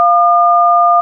Combination of 1209 Hz and 697 Hz sine waves, representing DTMF "1"
DTMF keypad frequencies (with sound clips)[12]